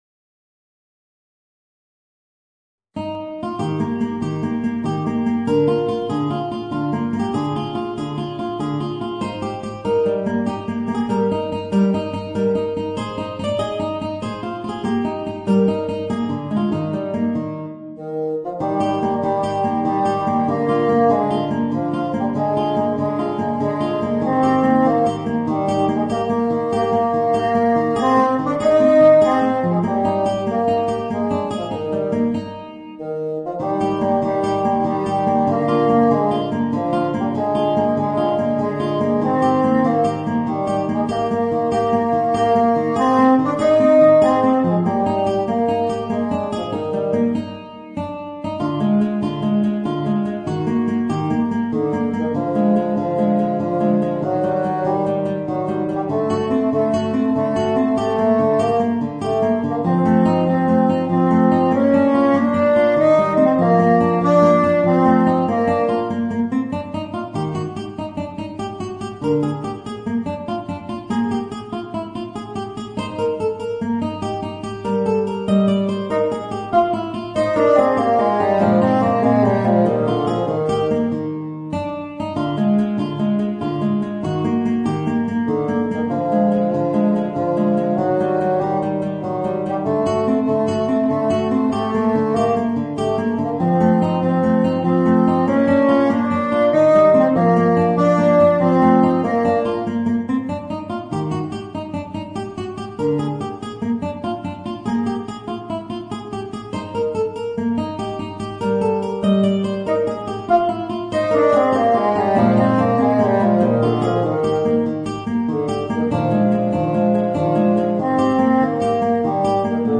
Voicing: Bassoon and Guitar